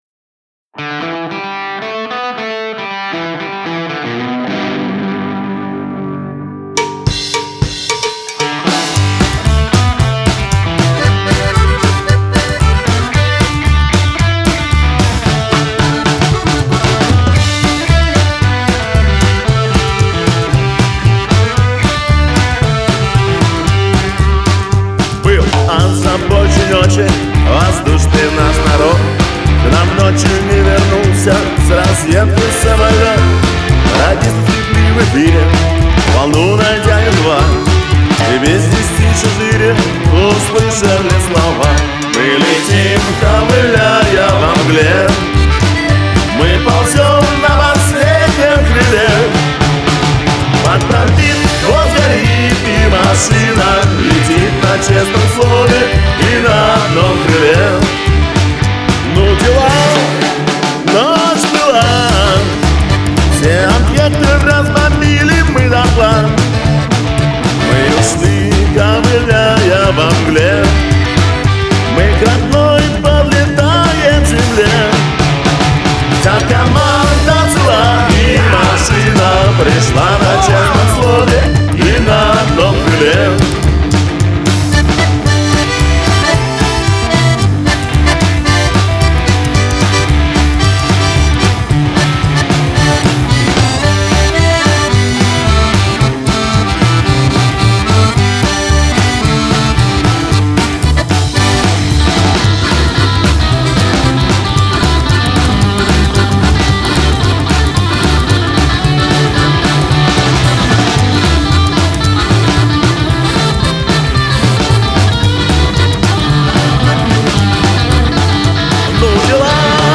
made it much more fervent